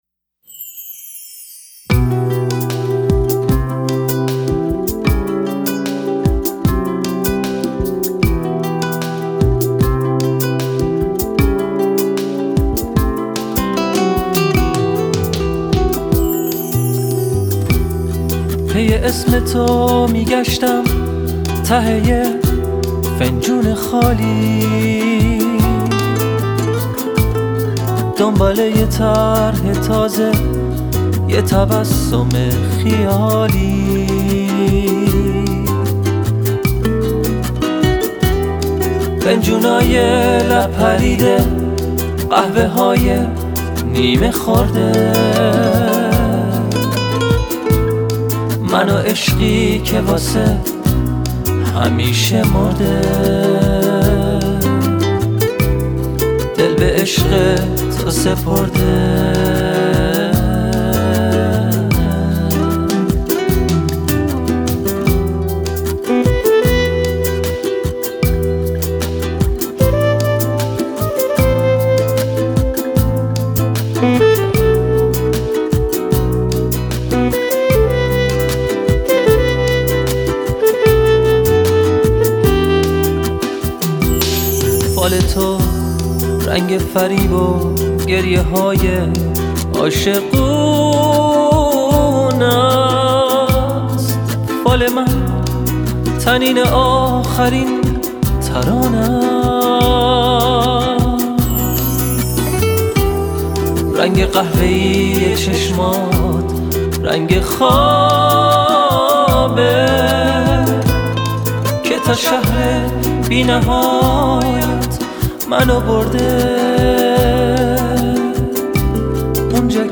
به سبک پاپ است.